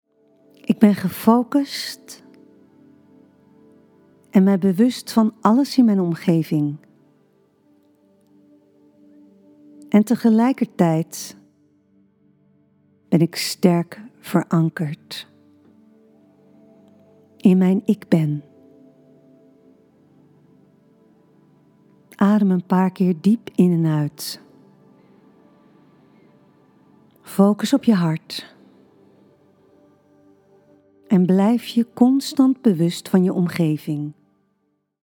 Meditatie “Focus voor onderweg”